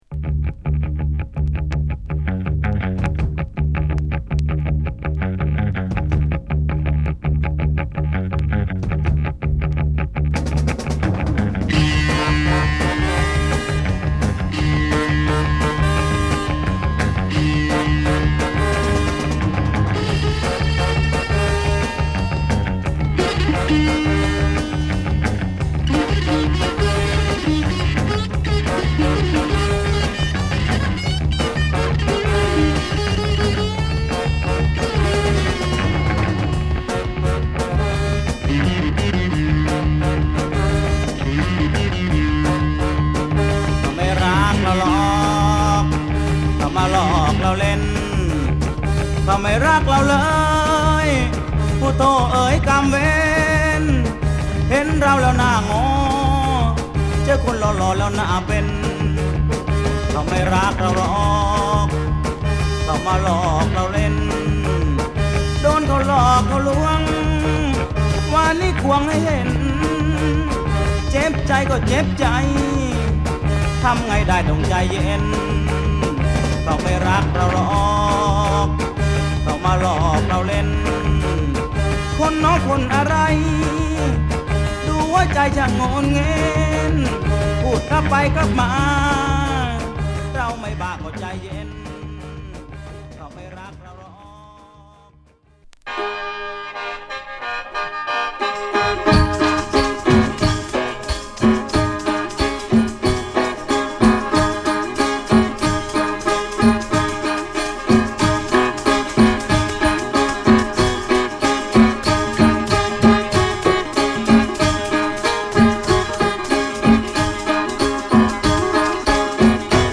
Thai
ケーンとピンとドラムがミニマルにループし交差するトランス度高い一曲です。